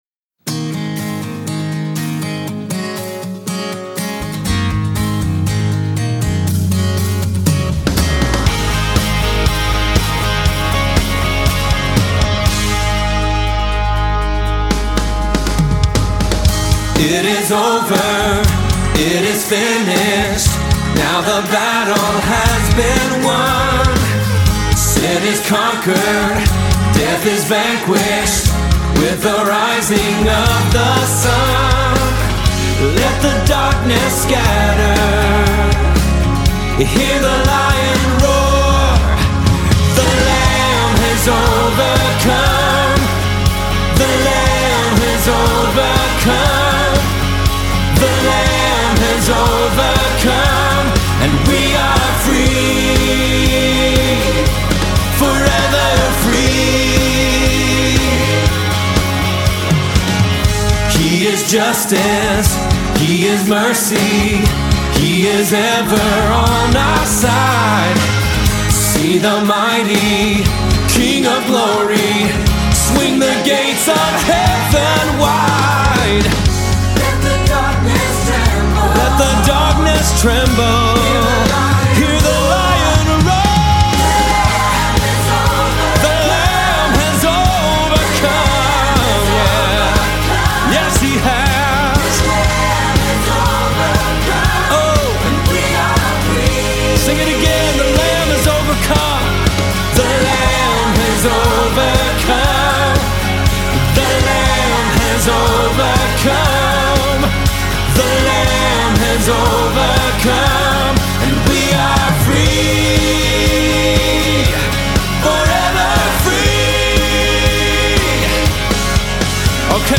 Summer Choir Anthems